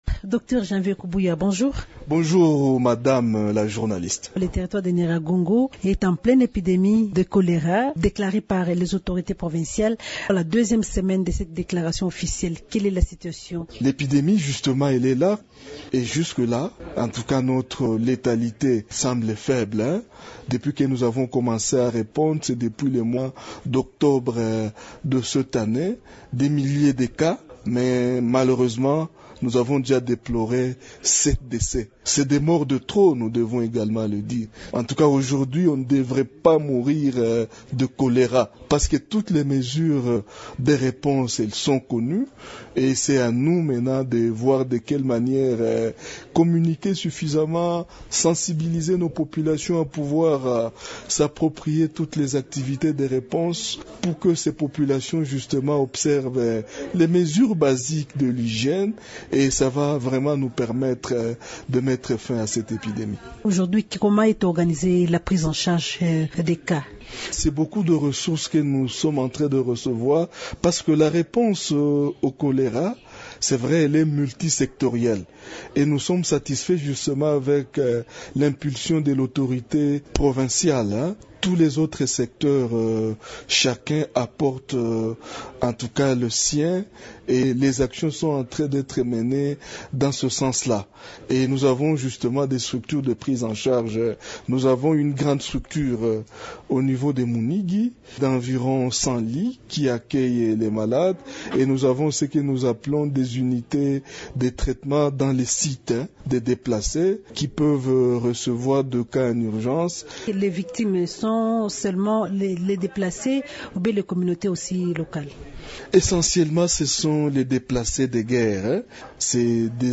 Invité de Radio Okapi ce lundi 26 décembre, le chef de division provinciale de la santé au Nord-Kivu, docteur Janvier Kubuya appelle à des actions plus importantes pour maîtriser cette épidémie.